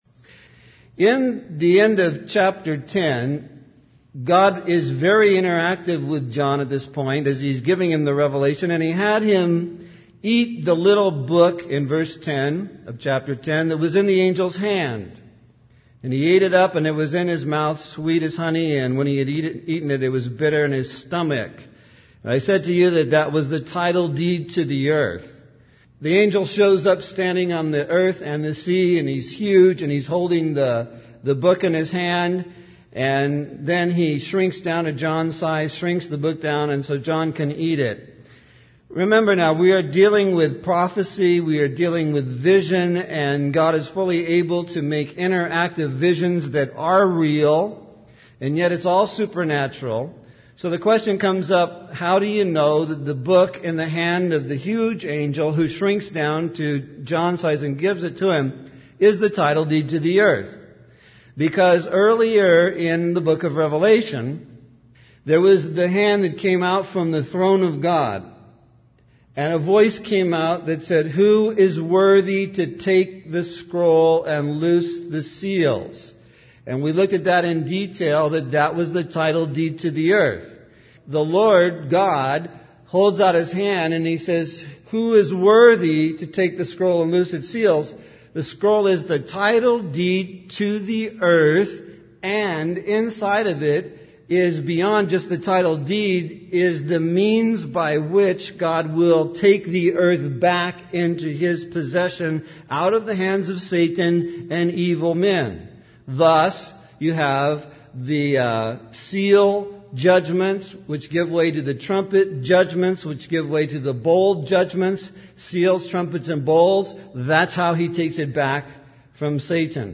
In this sermon, the preacher discusses a passage from the book of Revelation that describes a powerful event where believers are taken up to heaven. The preacher emphasizes the fear and awe that this event brings upon those who witness it.